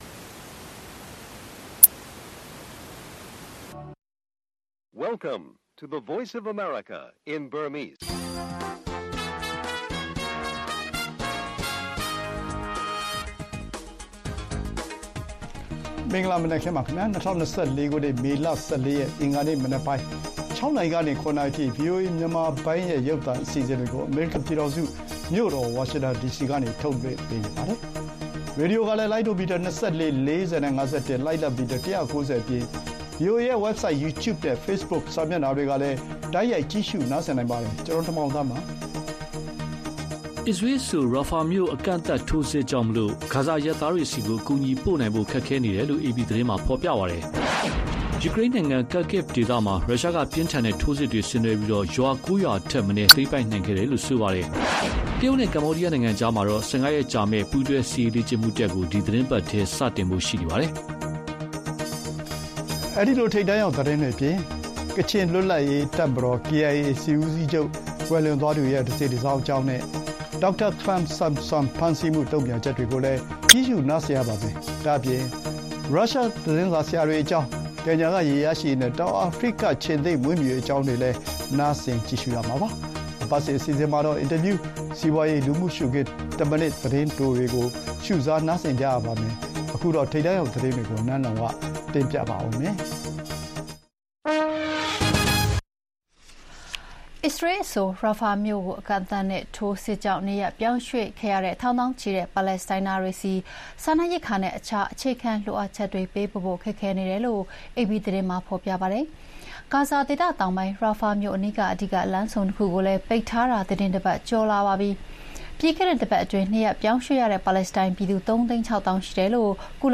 ဗွီအိုအေမြန်မာနံနက်ခင်း(မေလ ၁၄၊ ၂၀၂၄) ဂါဇာအရပ်သားတွေဆီ အကူအညီပေးဖို့ ခက်ခဲနေ၊ ယူကရိန်းအရှေ့ခြမ်းစစ်မျက်နှာပြင် ရုရှားထိုးစစ်အသာရ စတဲ့သတင်းတွေနဲ့ အပတ်စဉ်ကဏ္ဍတွေမှာ တွေ့ဆုံမေးမြန်းခန်း၊ စီးပွားရေး၊ လူမှုရှုခင်း၊ သက်တံရောင်းသတင်းလွှာတို့ ထုတ်လွှင့်ပေးပါမယ်။